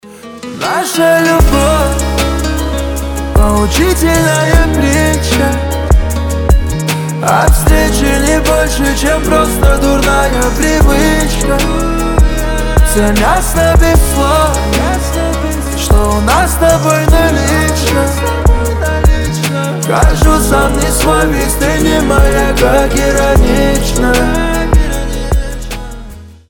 • Качество: 320, Stereo
лирика